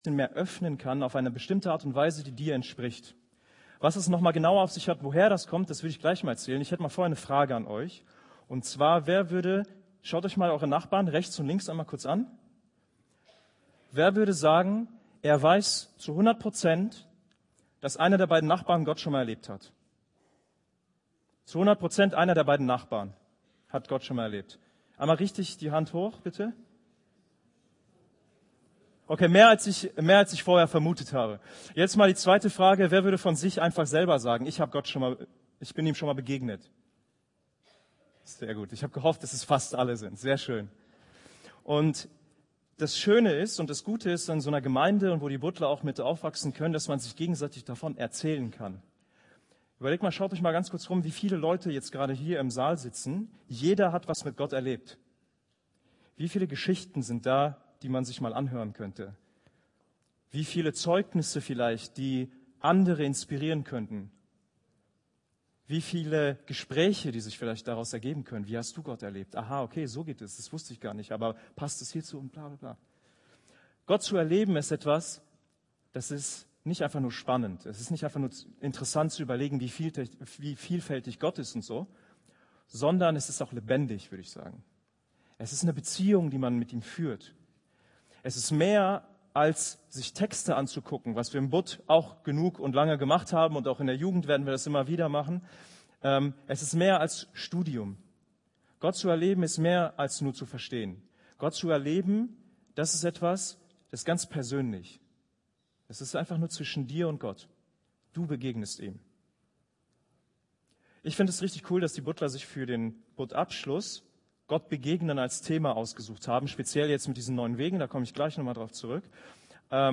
Jugendgottesdienst , Predigt